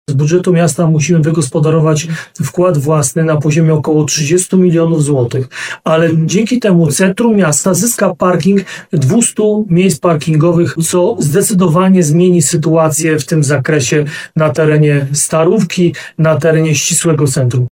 Jak informuje prezydent Ludomir Handzel, miasto Nowy Sącz dostało ponad 56 milionów złotych z funduszy europejskich, ale to nie pokryje wszystkich kosztów prac.